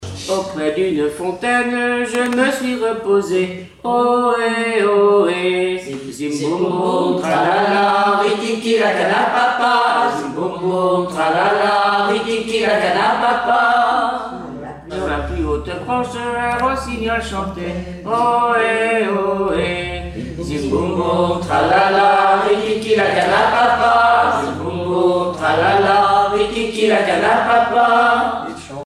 Chansons et formulettes enfantines
Pièce musicale inédite